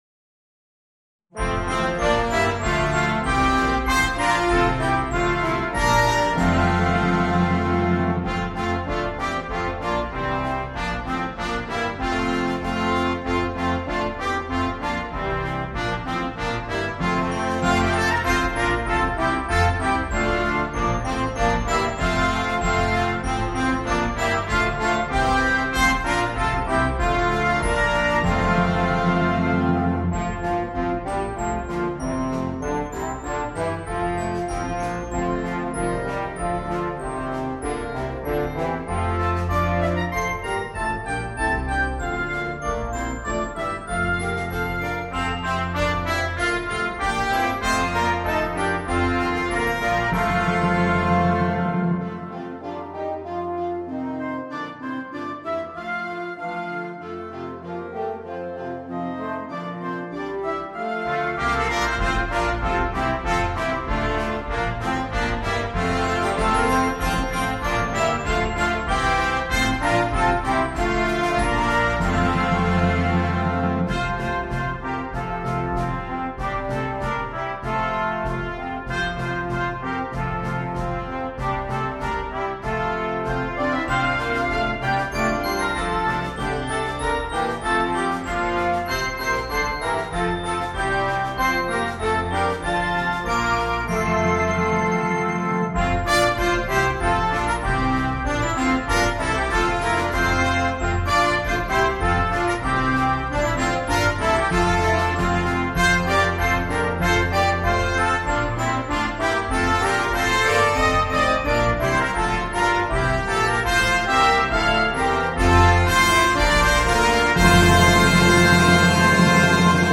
The MP3 was recorded with NotePerformer 3.
Christmas